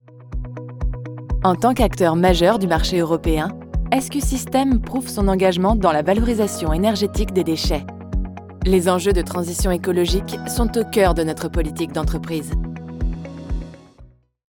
Jong, Natuurlijk, Vriendelijk, Zakelijk
Corporate